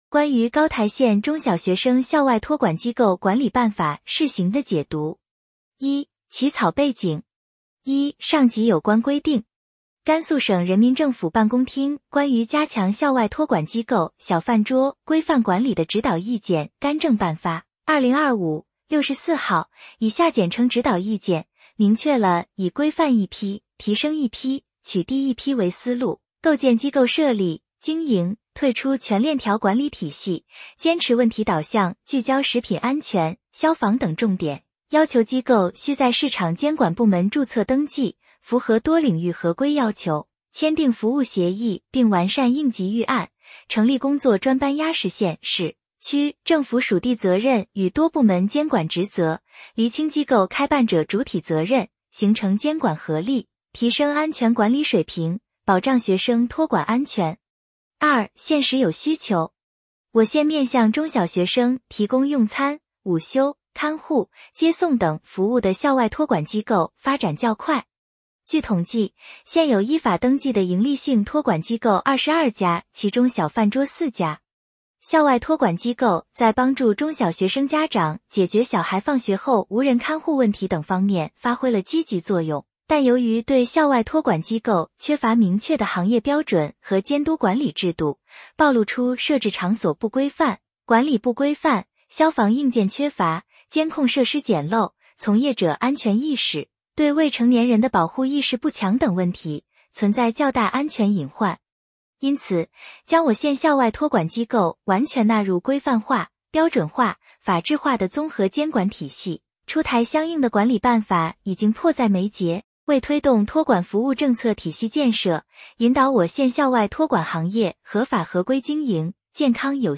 【音频解读】《高台县中小学生校外托管机构管理办法(试行)》解读